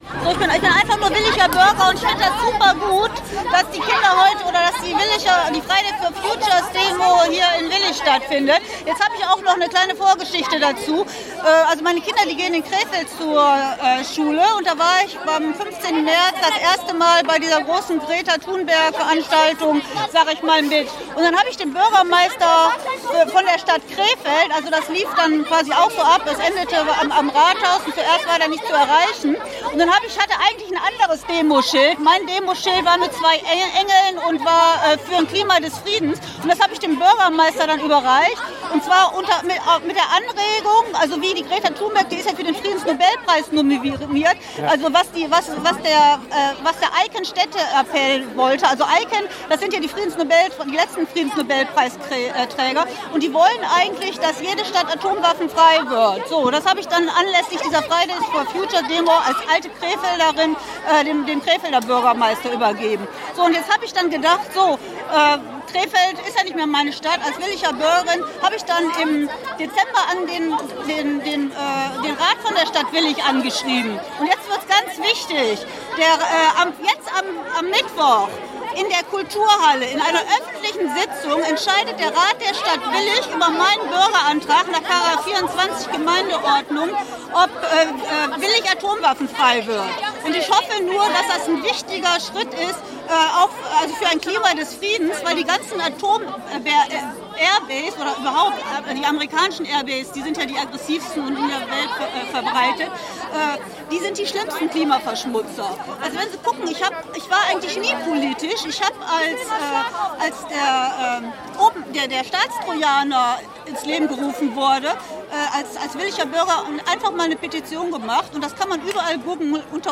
Impressionen des Marschs der Klimastreikenden